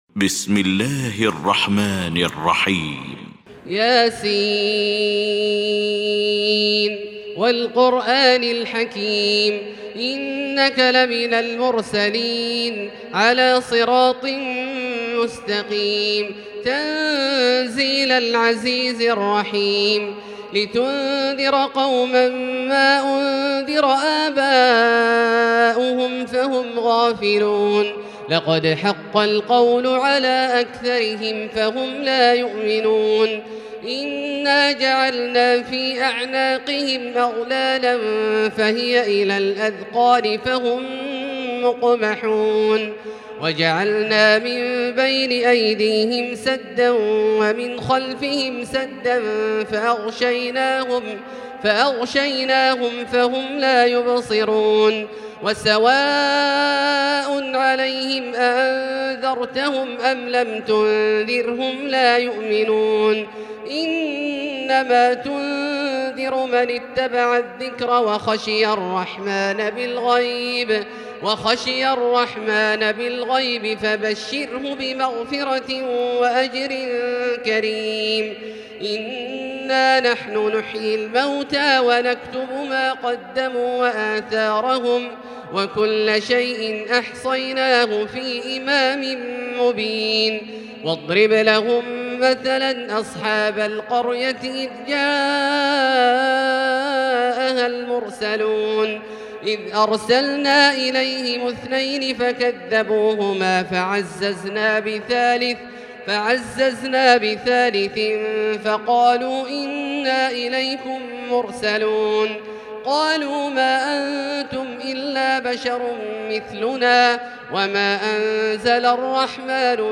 المكان: المسجد الحرام الشيخ: فضيلة الشيخ عبدالله الجهني فضيلة الشيخ عبدالله الجهني يس